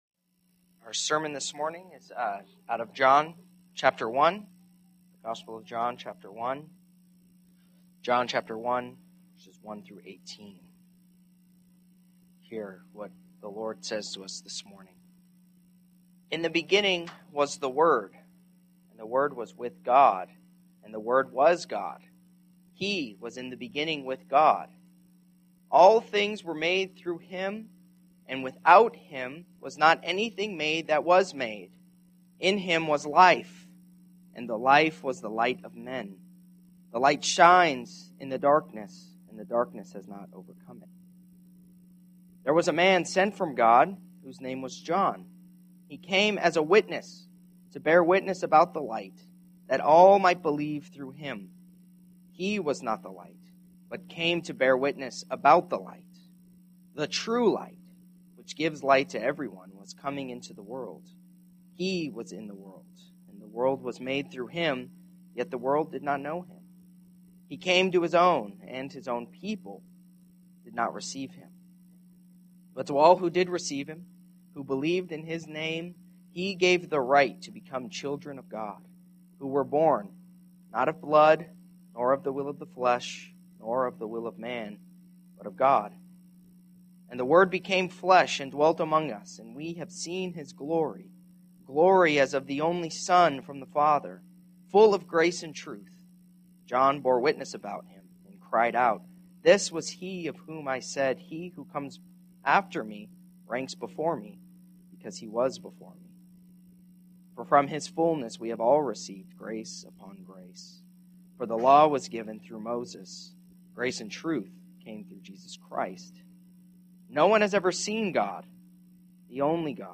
Passage: John 1:1-18 Service Type: Morning Service Bible Text